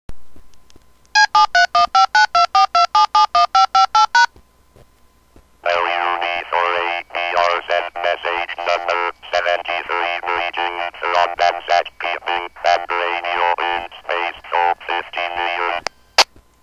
APRStt uses DTMF for sending callsigns and grid square position reports and the spacecraft acknowledges the uplinks by voice response.
Here are some of the Voice Responses to DTMF uplinks: